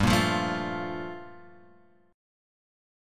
Gm9 chord {3 1 3 2 x 1} chord